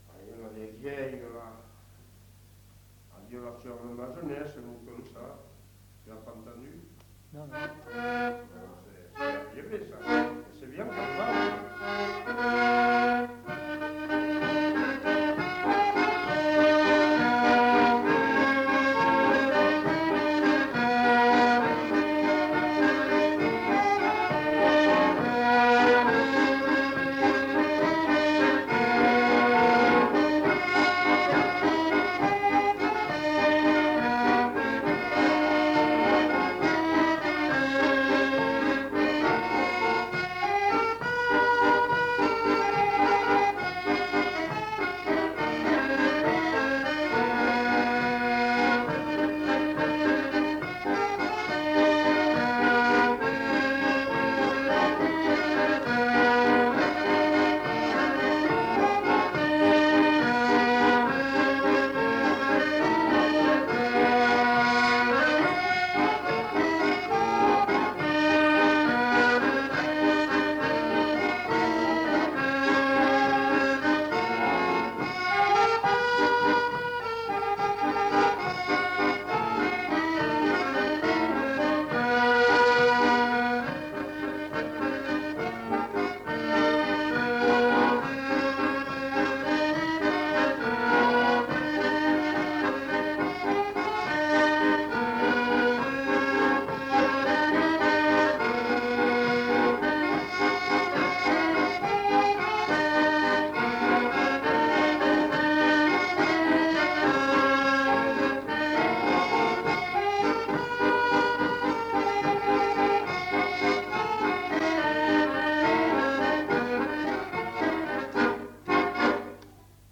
Aire culturelle : Viadène
Département : Aveyron
Genre : morceau instrumental
Instrument de musique : accordéon chromatique
Danse : valse